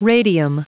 Prononciation du mot radium en anglais (fichier audio)
Prononciation du mot : radium